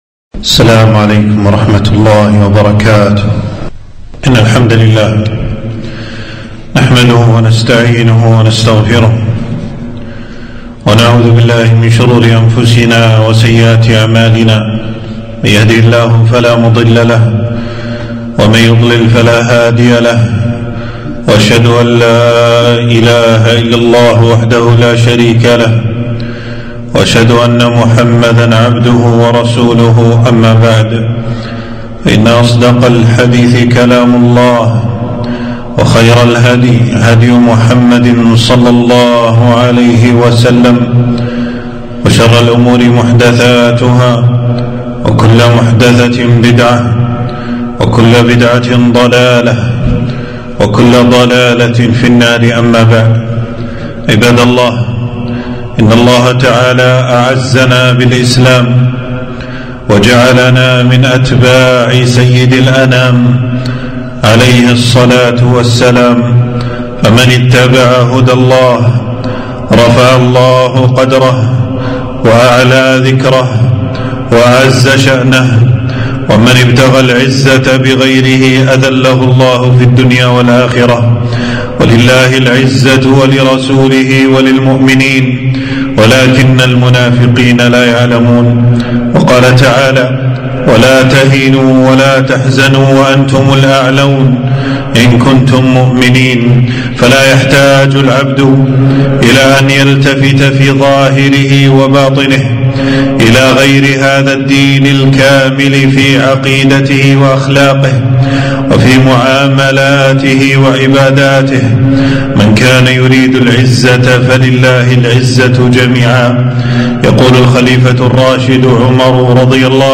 خطبة - الحذر من مشابهة الكفار في أعيادهم وعباداتهم (عيد الحب واليوغا)